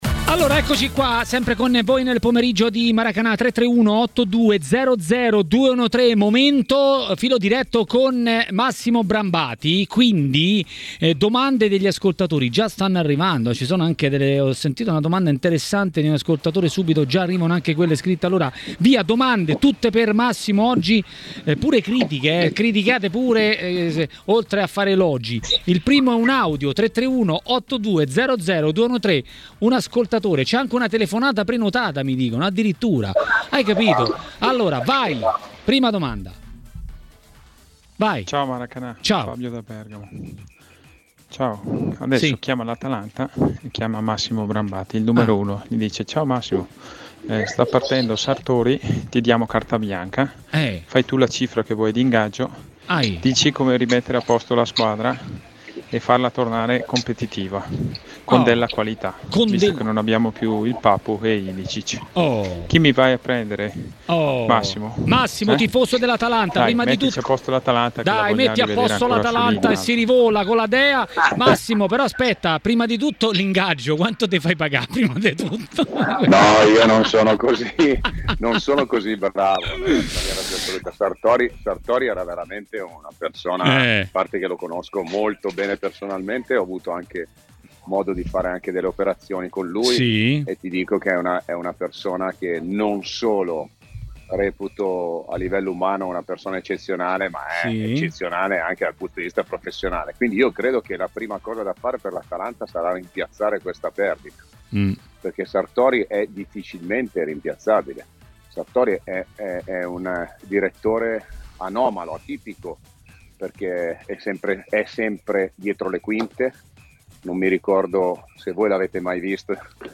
a Maracanà, nel pomeriggio di TMW Radio, ha detto la sua sui temi del giorno.